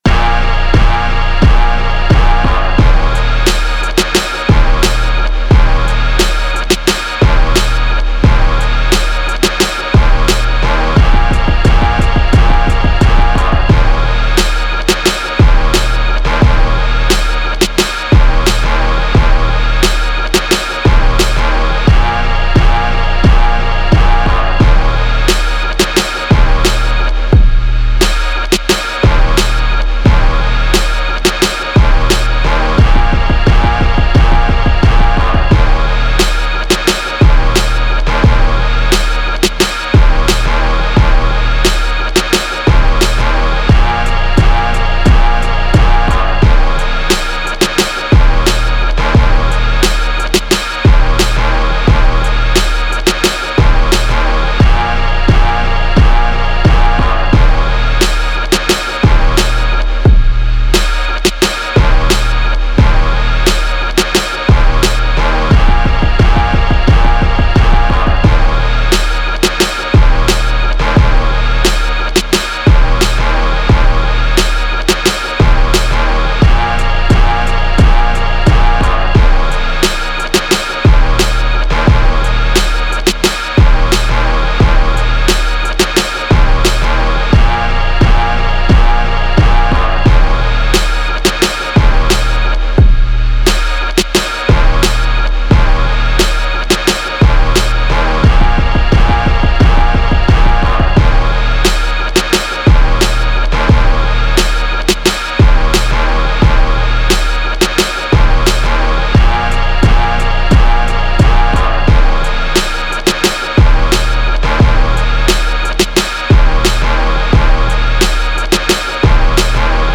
Hip Hop, 90s
Ab Major